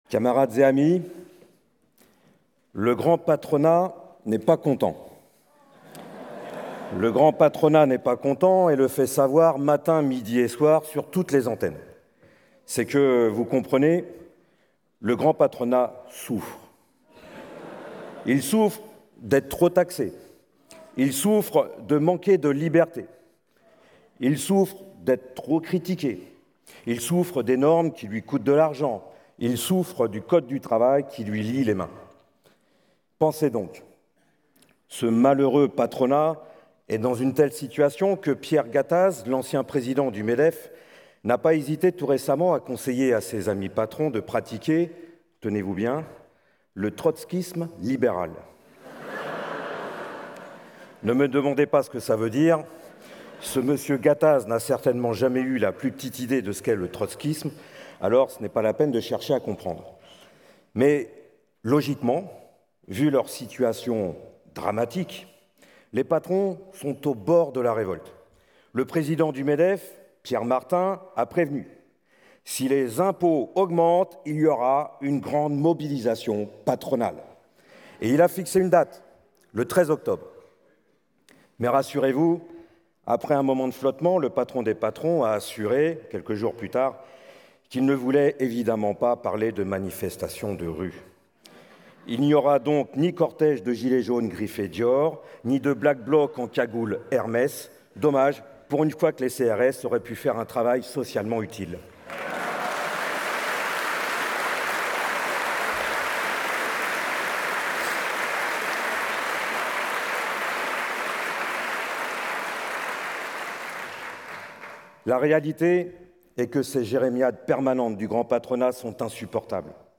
Meeting du 27 septembre 2025 à Paris